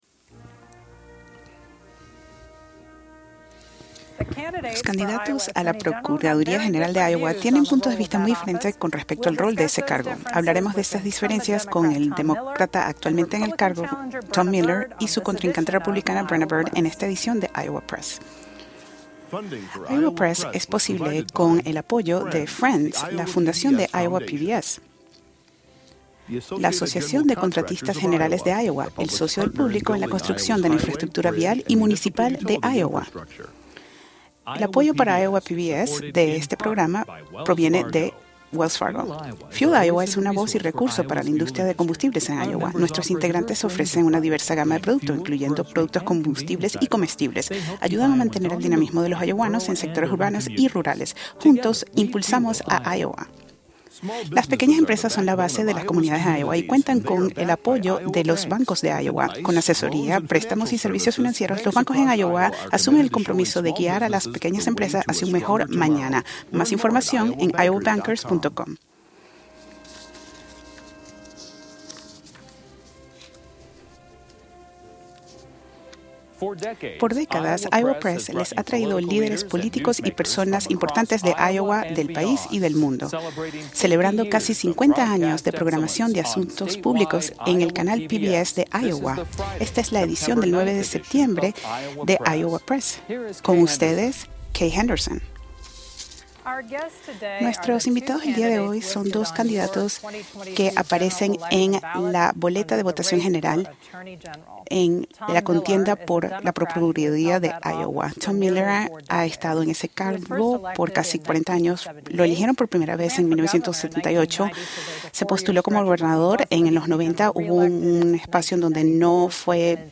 En esta edición de Iowa Press, nuestros invitados son los candidatos a la procuraduría general de Iowa, Brenna Bird (R-Dexter) y Tom Miller (D-Des Moines), actual procurador general de Iowa.
Ambos candidatos en campaña por este cargo responderán a las preguntas de los reporteros y hablarán acerca de sus plataformas, inquietudes y planes a futuro.